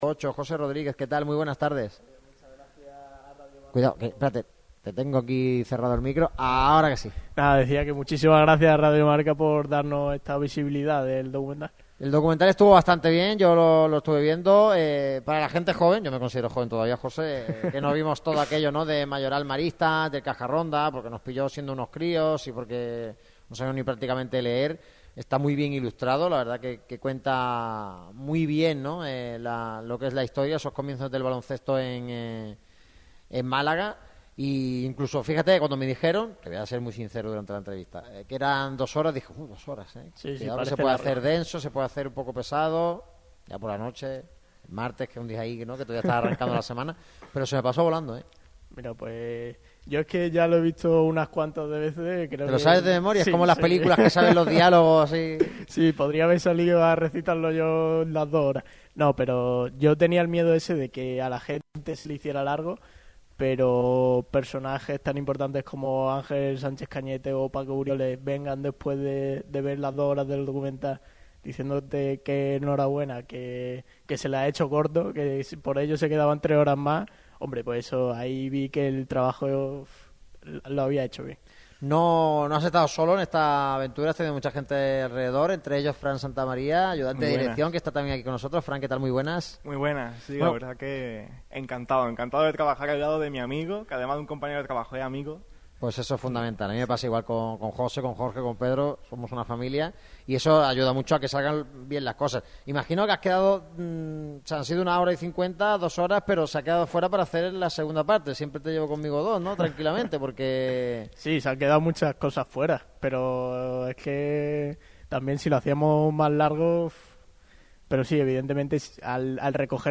pasan por el micrófono rojo de Radio Marca Málaga un día antes de que vea la luz.